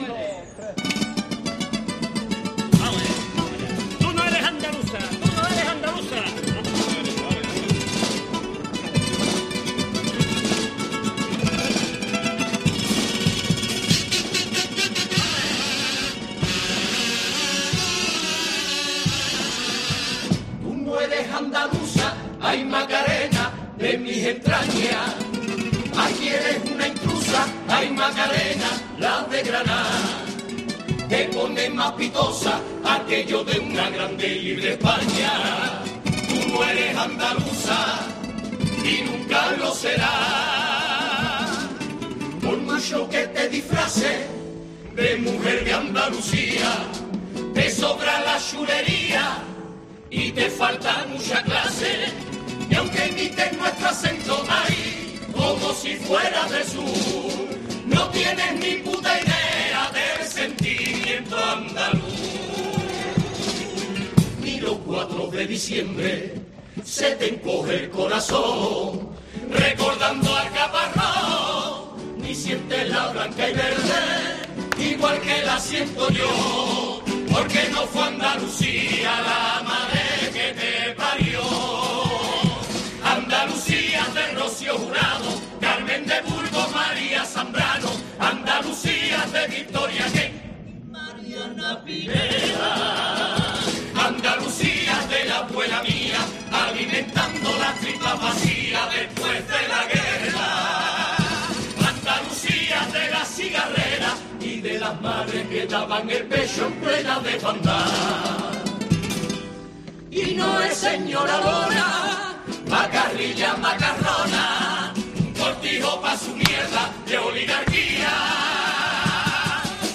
AUDIO: Pasodoble de Los quinquis a la candidata de Vox Macarena Olona
Carnaval